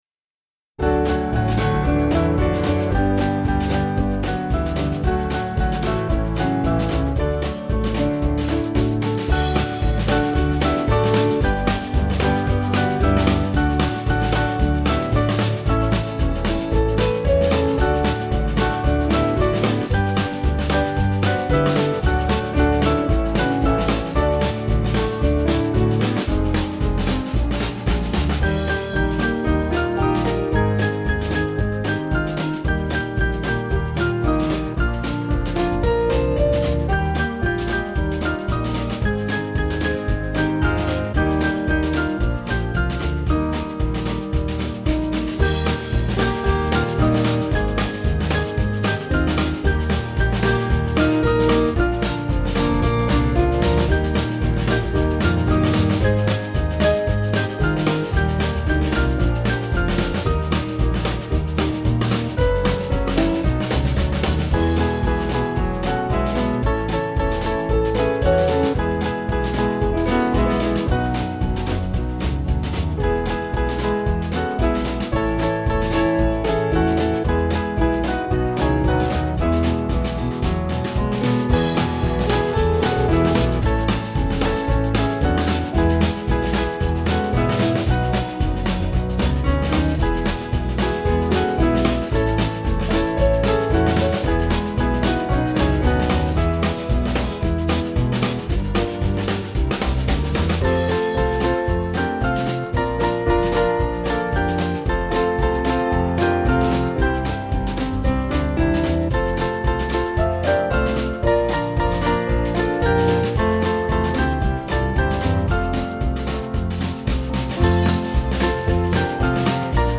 Small Band
(CM)   3/D-Eb-E-F